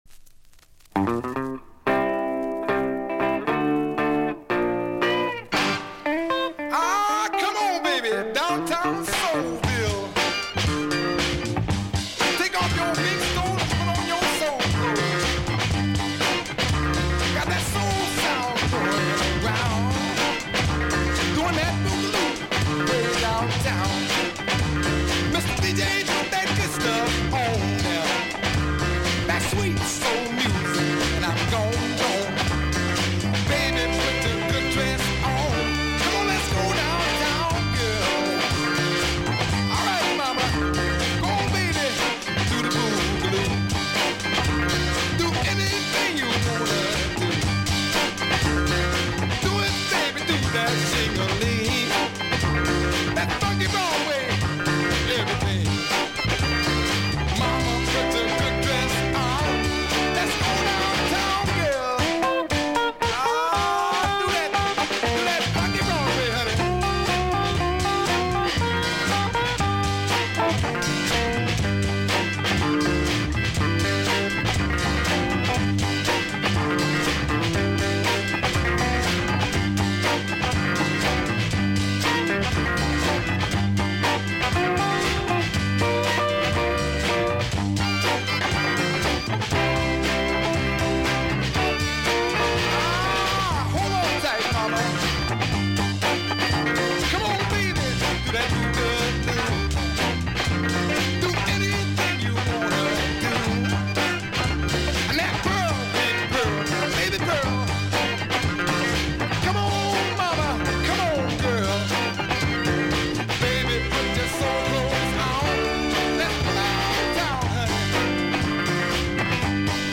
Music behind DJ